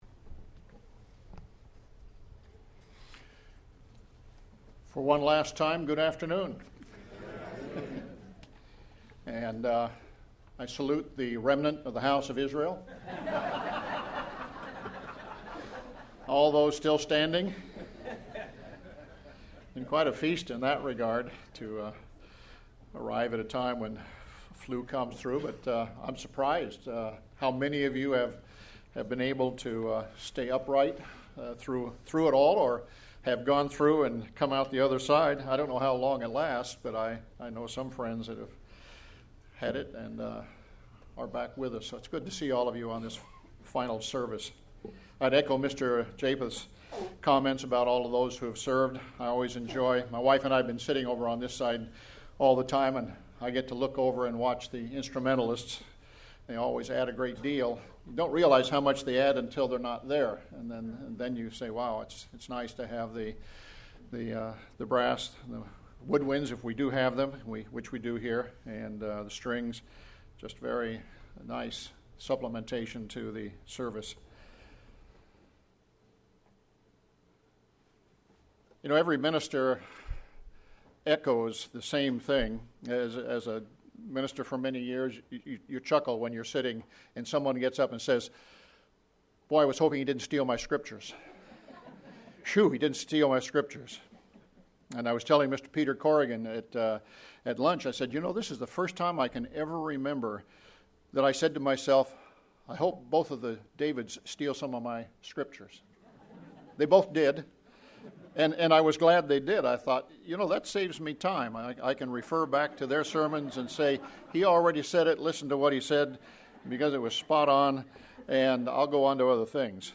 This sermon was given at the Victoria, British Columbia 2016 Feast site.